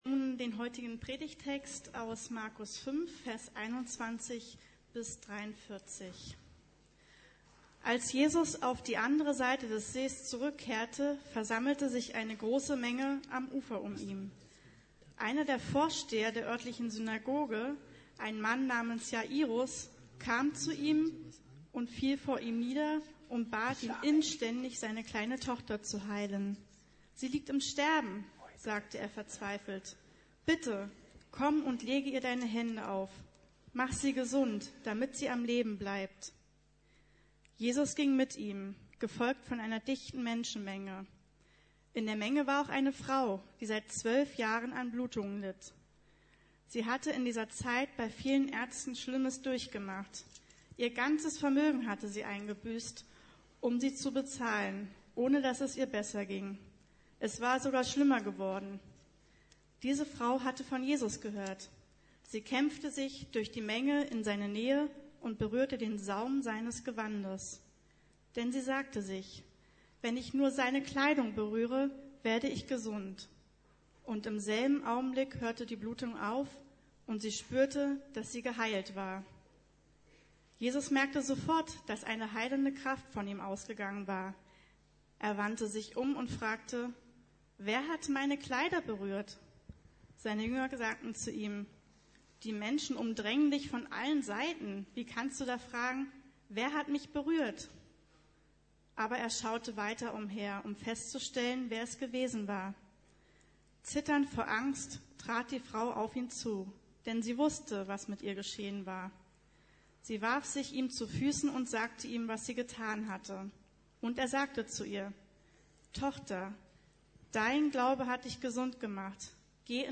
Faszination Jesus II: Unerschütterliches Vertrauen ~ Predigten der LUKAS GEMEINDE Podcast